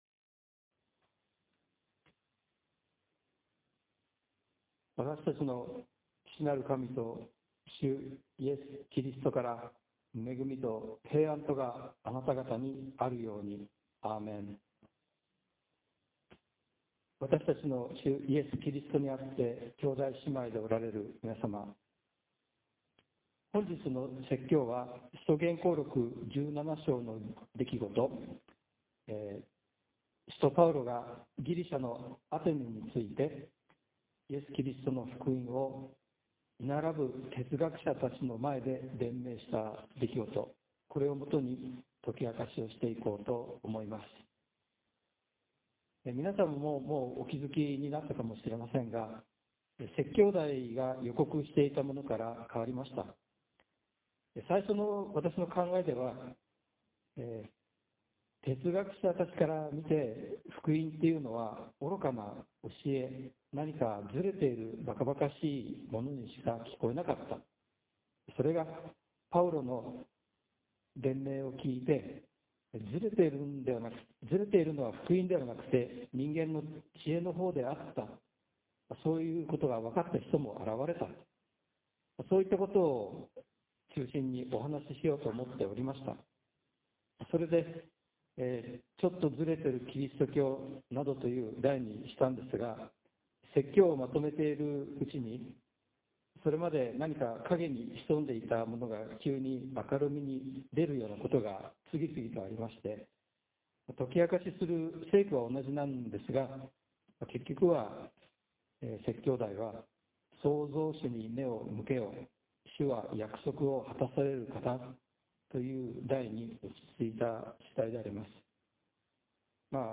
説教音声 – ページ 2 – 福音ルーテル スオミ・キリスト教会